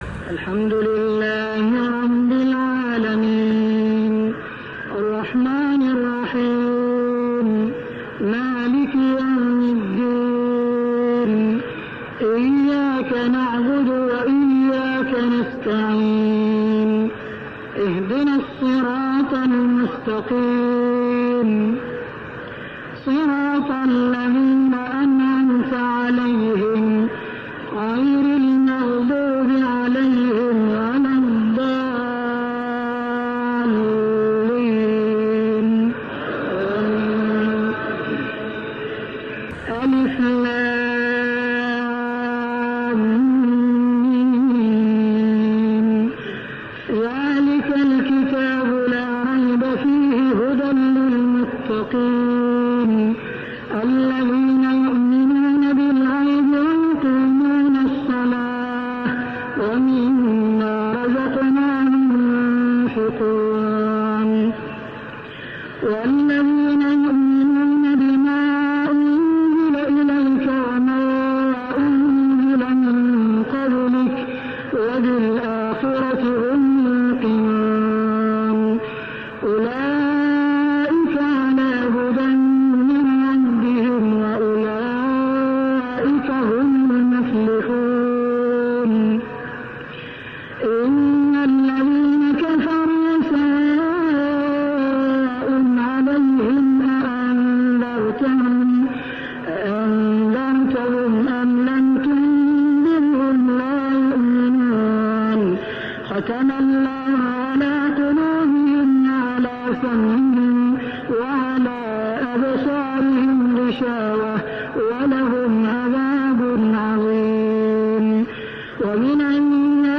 صلاة التراويح ليلة 1-9-1409هـ سورتي الفاتحة كاملة و البقرة 1-141 | Tarawih prayer Surah Al-Fatihah and Al-Baqarah > تراويح الحرم المكي عام 1409 🕋 > التراويح - تلاوات الحرمين